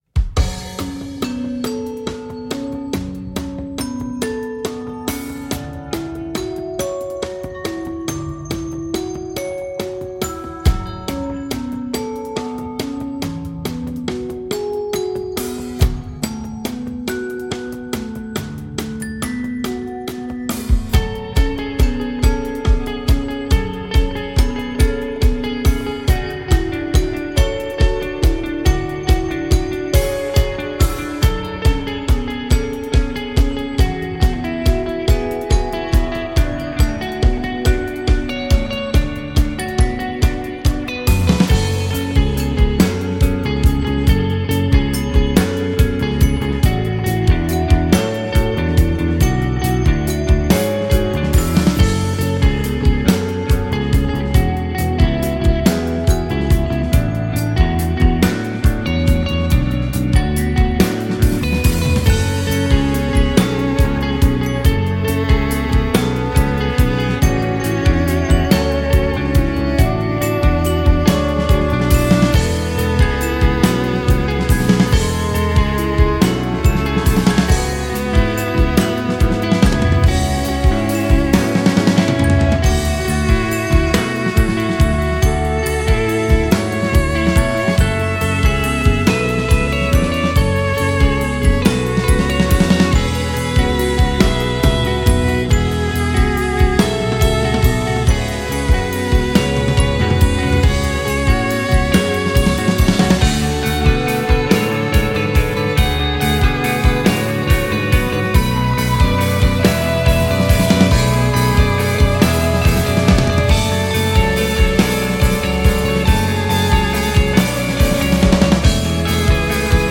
a beautiful cinematic piece of violin-laced post rock